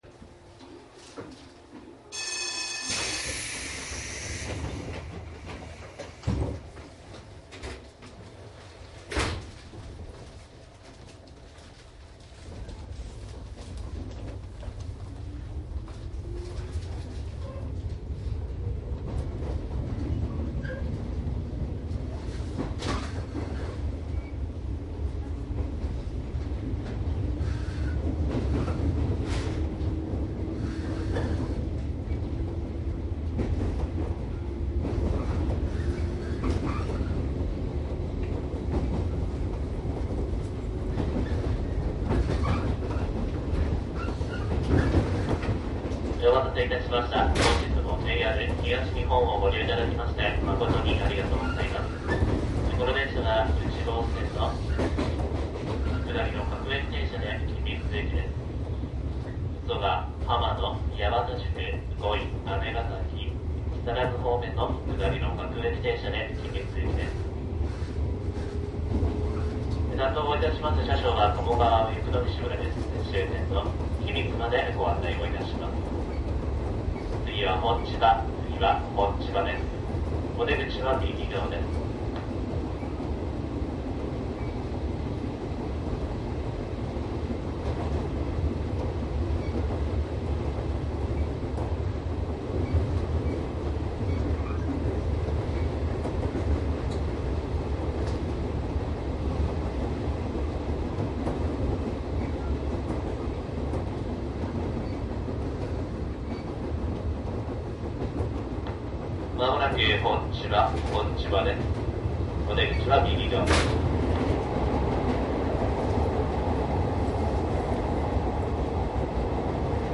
内容は♪JR内房線 113系走行音の収録です。
マスター音源はデジタル44.1kHz16ビット（マイクＥＣＭ959）で、これを編集ソフトでＣＤに焼いたものです。